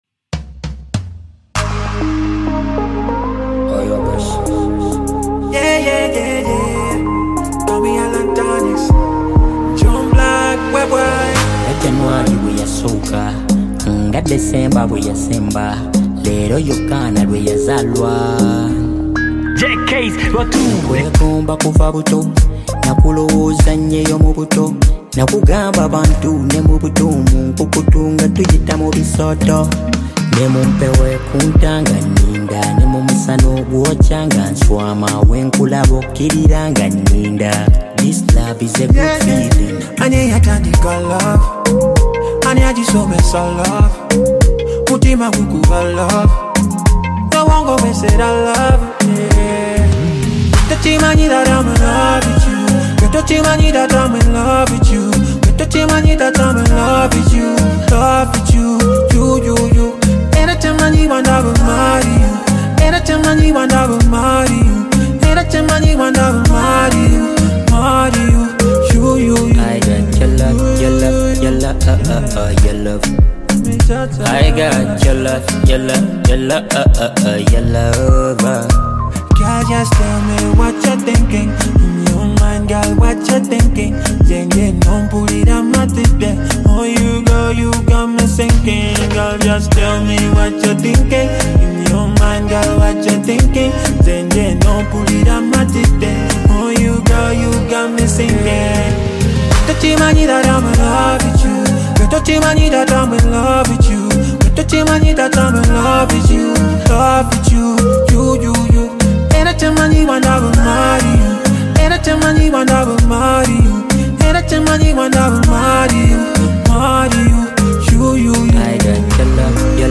RnB And Afro Beat singer